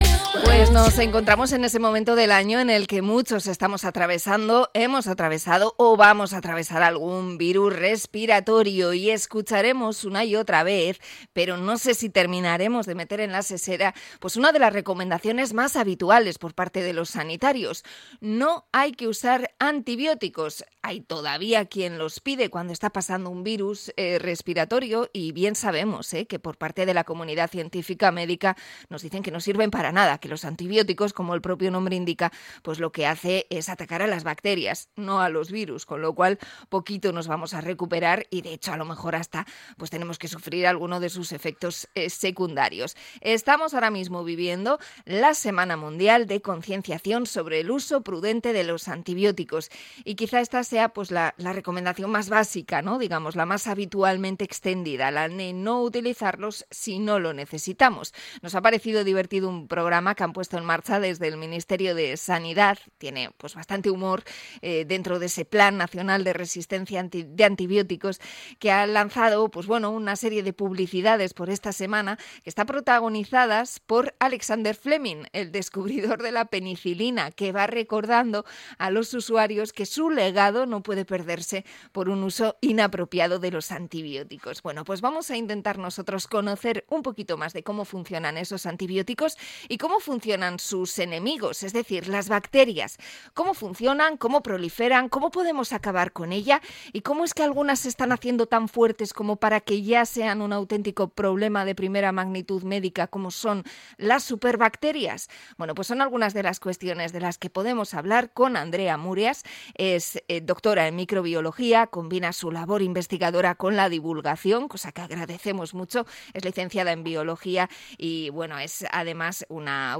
Entrevista a experta sobre las superbacterias